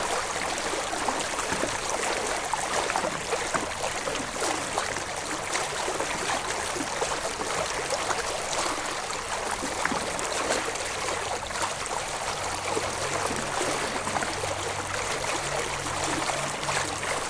Catamaran Cutting Through Water, Clean